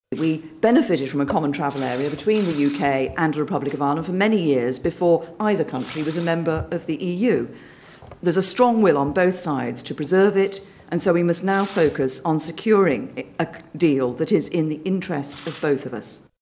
Mrs May says both the Irish and British governments are willing to find a mutually beneficial agreement on free movement: